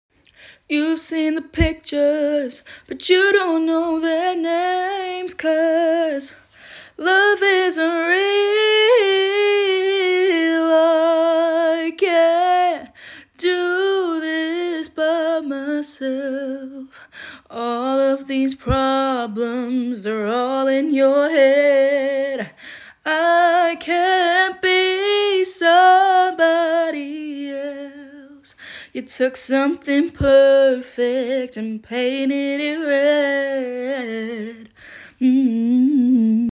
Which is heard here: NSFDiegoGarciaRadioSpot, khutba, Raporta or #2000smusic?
#2000smusic